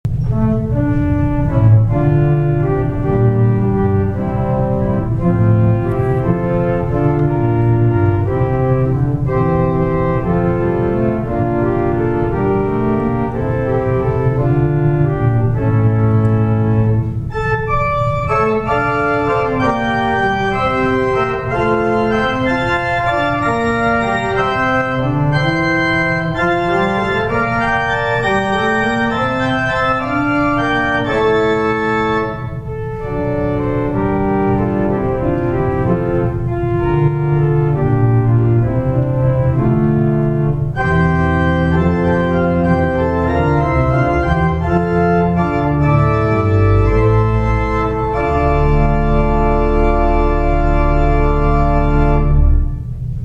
First Avenue Presbyterian, Evansville
Sound clips from the Evansville AGO Historic Organs Recital
Pneumatic Key and Stop Action